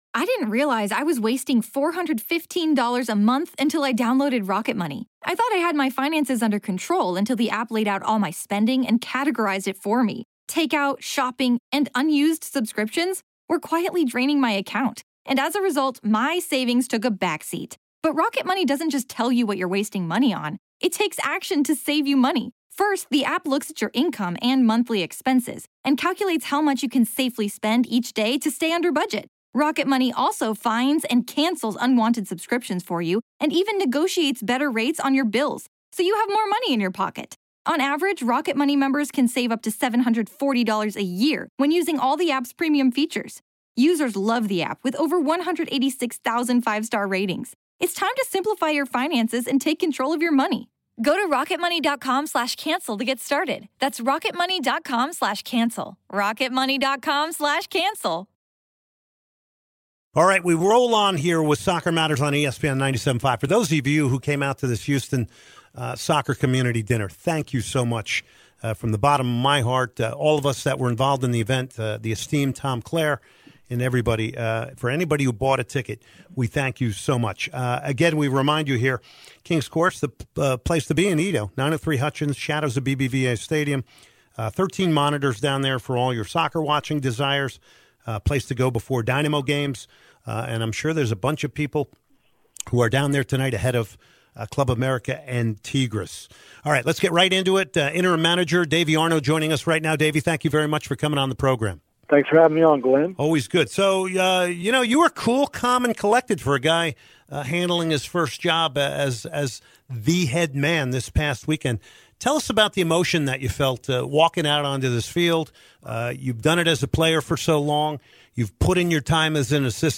Interview with Dynamo Interm Manager.